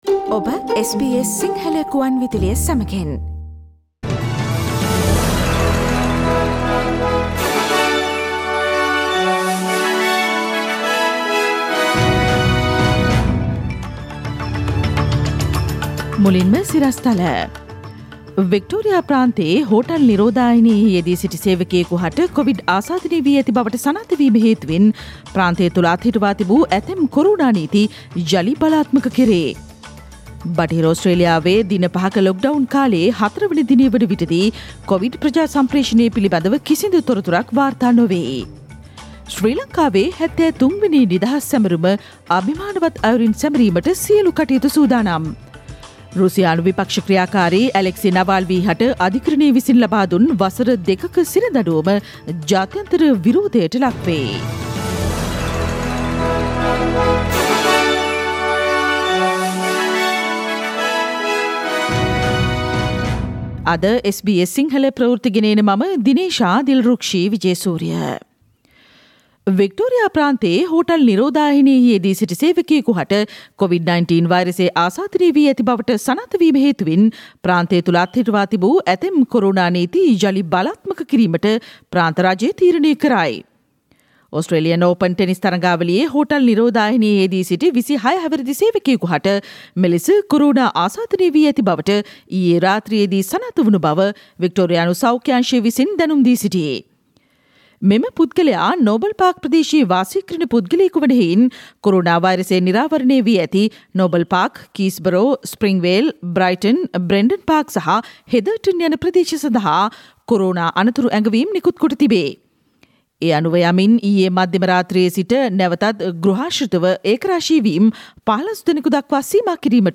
While Victoria revering Corona restrictions, Western Australia is on severe bushfire fire threat: SBS Sinhala radio news on 4 February 2021